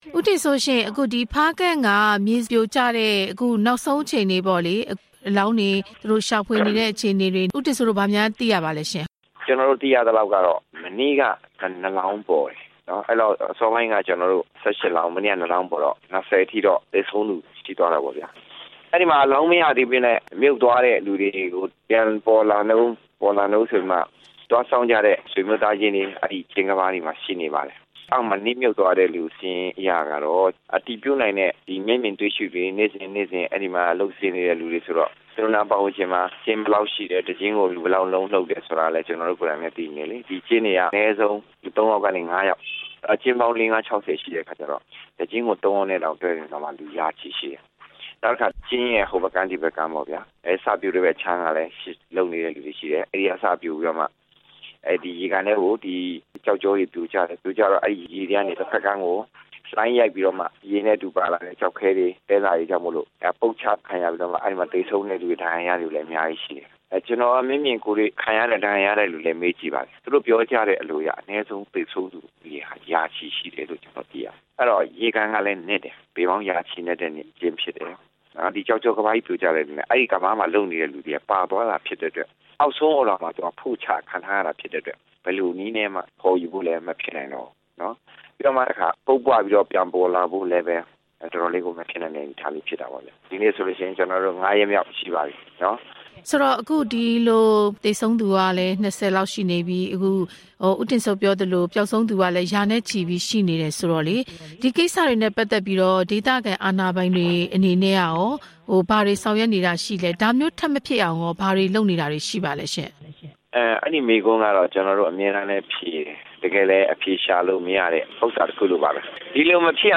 ဖားကန့် ကျောက်စိမ်းလုပ်ကွက်မြေပြိုမှု ဆက်သွယ်မေးမြန်းချက်